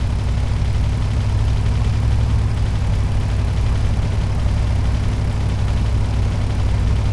car-engine-1.ogg